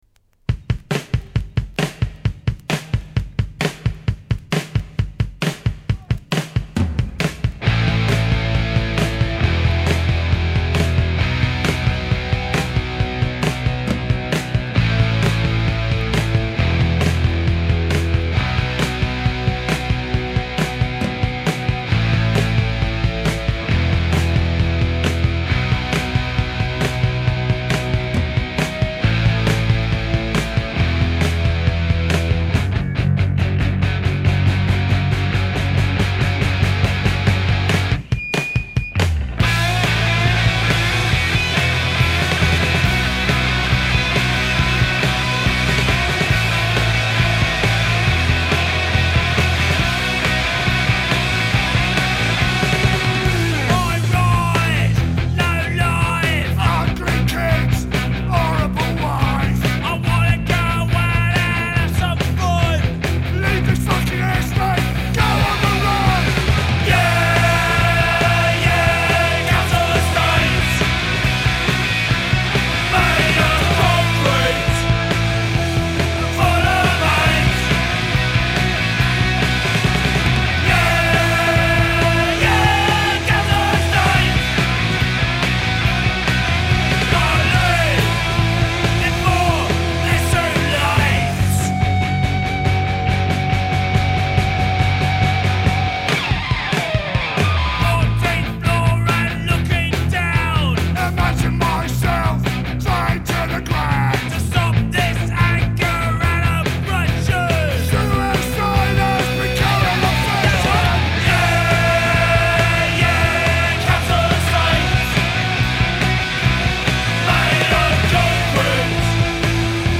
Firmly mid-tempo, with giant guitar leads